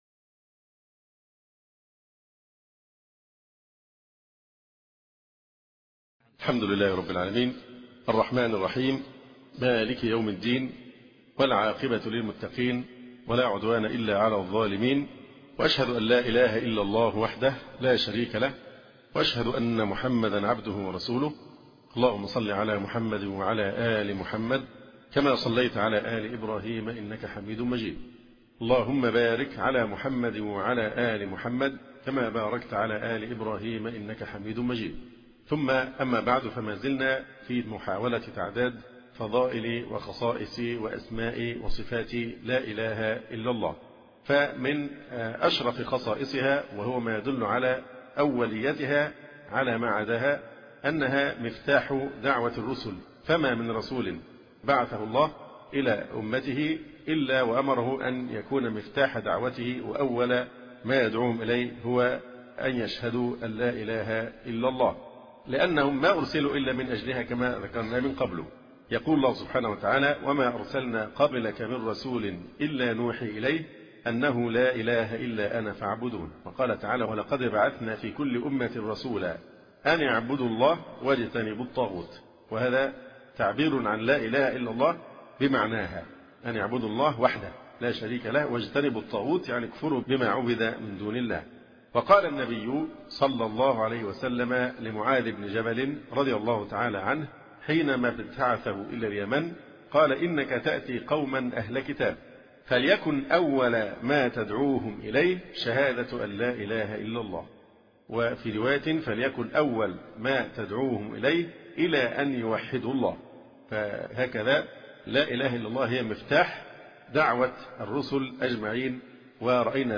04- فضائل كلمة التوحيد (الدرس الرابع) (شرح قضايا الإيمان والكفر